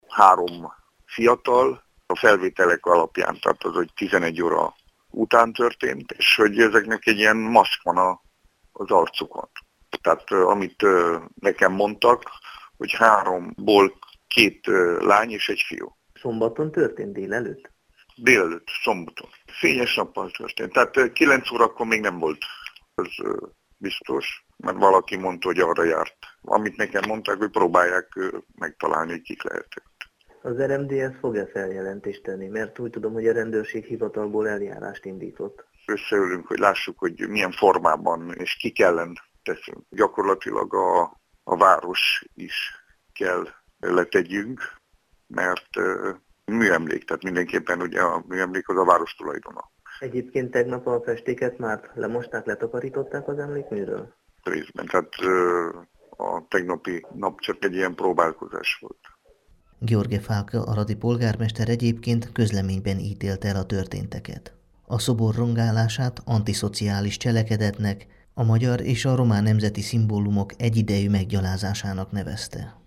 A felvételek alapján három álarcos fiatal fújta le piros, sárga és kés festékspray-jel a 13 vértanú közül hatnak a domborművét, és írt obszcén szavakat a talapzatra – mondta érdeklődésünkre Bognár Levente aradi alpolgármester, a megyei RMDSZ elnöke.